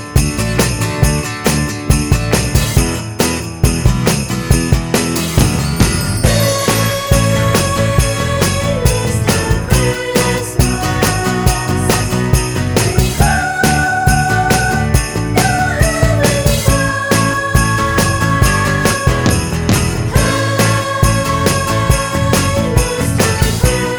Kids Backing Vocals Only Rock 'n' Roll 3:22 Buy £1.50